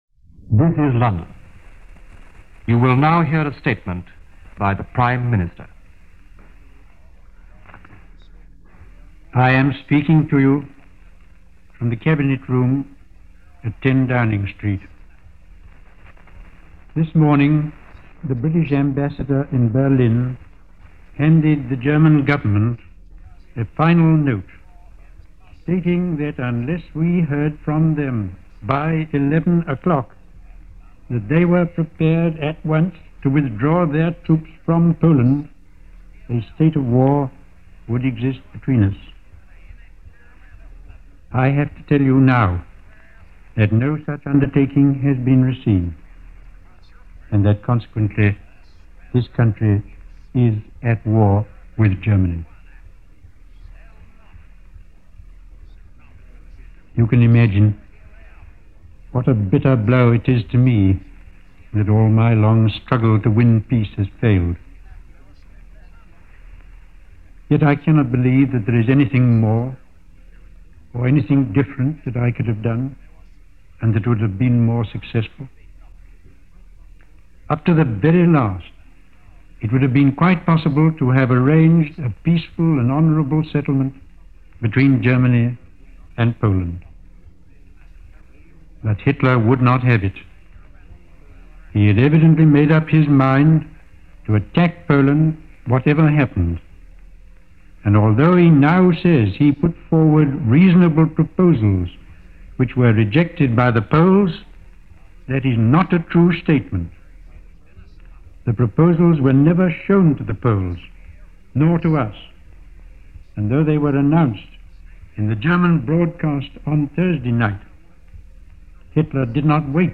– BBC Home Service – Prime Minister Neville Chamberlain – declaration of War – September 1, 1939 – Gordon Skene Sound Collection –
A short address – one which didn’t need to convey more than it already did.